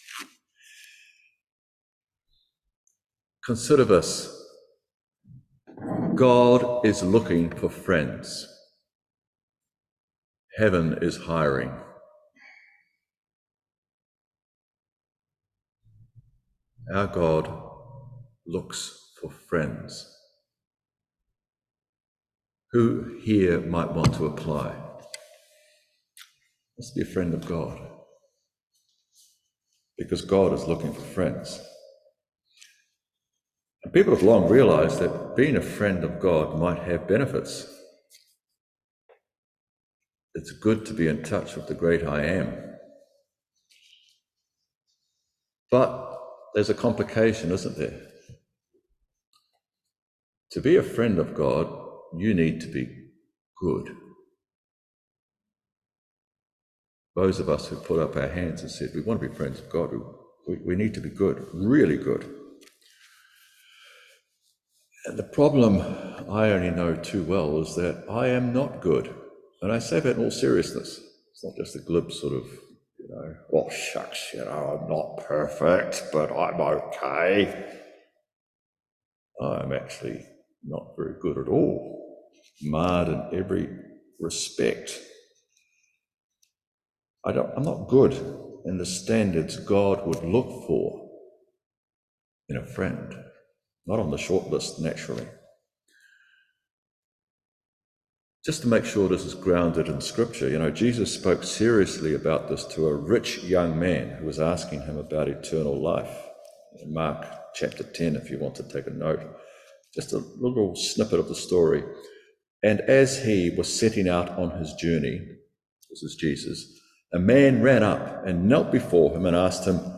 18-26 Service Type: Morning Worship Our righteousness with God.